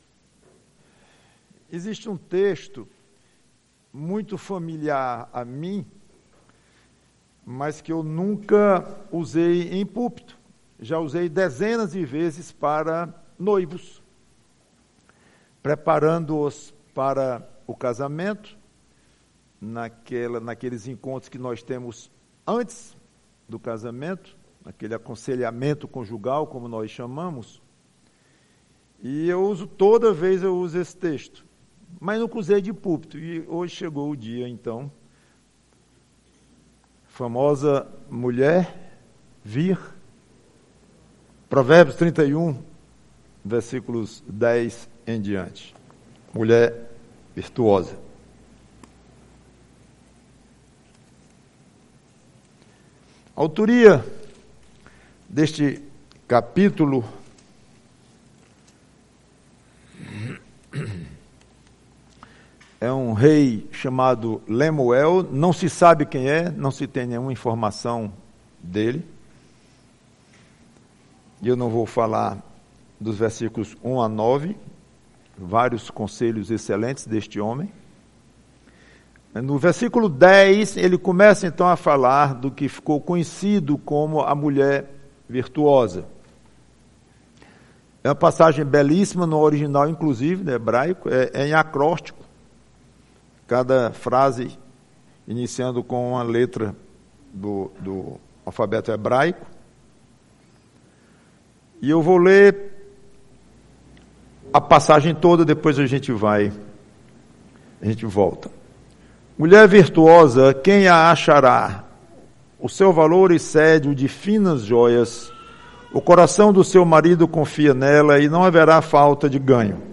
PREGAÇÃO Mulher virtuosa, quem a achará?